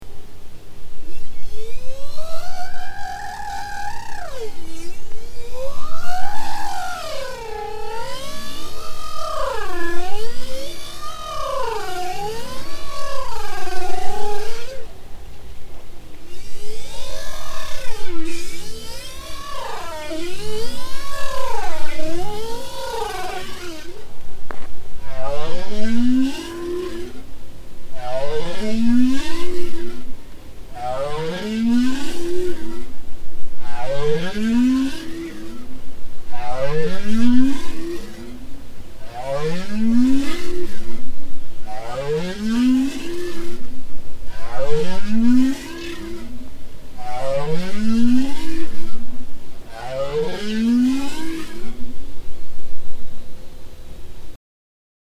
Les vocalisations d’une baleine boréale.